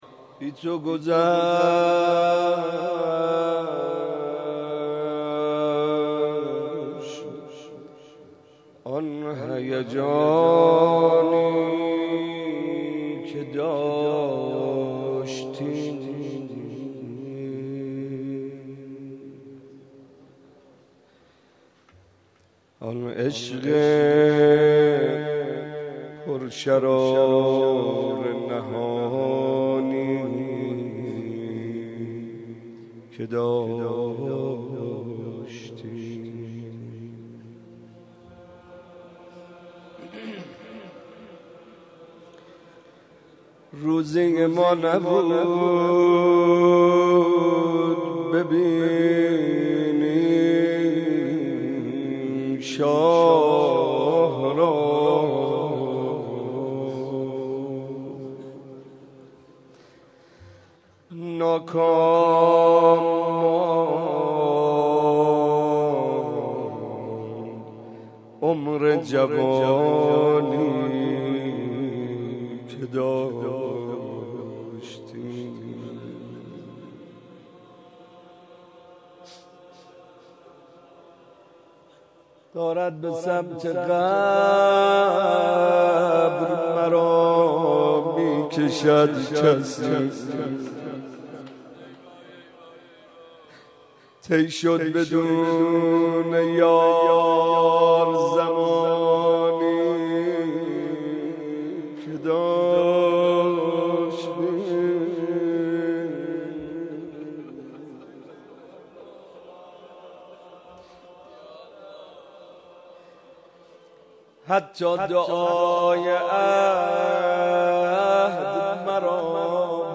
01.monajat.mp3